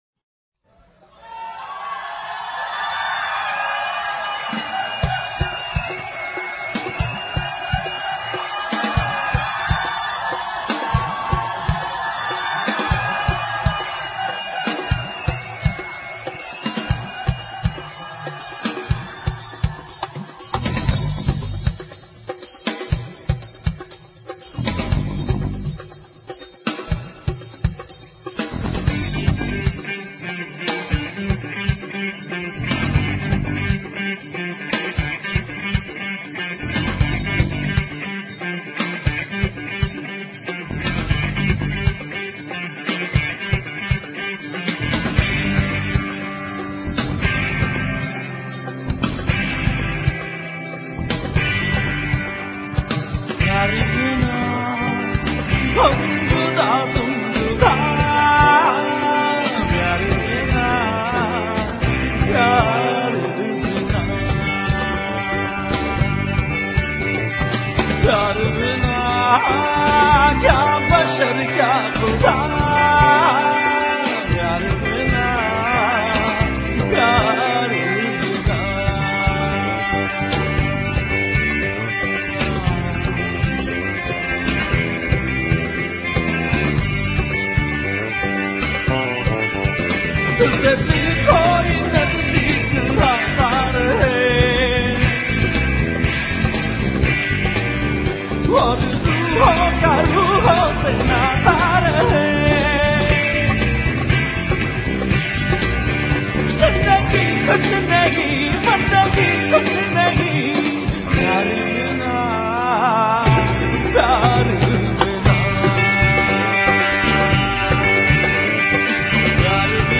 Pakistani Songs